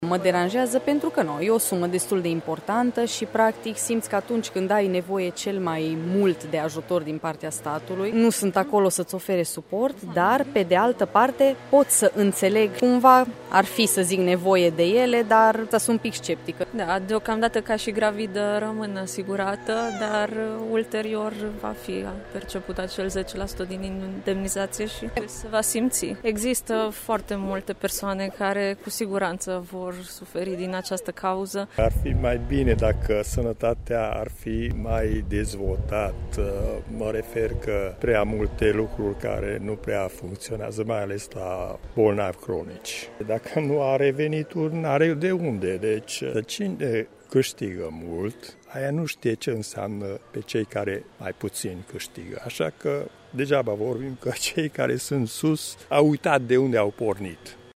În loc să fie protejată familia și încurajată natalitatea, statul român procedează exact invers, spun târgumureșenii care sunt nemulțumiți că, deși plătesc CASS, nu beneficiază de servicii medicale corespunzătoare: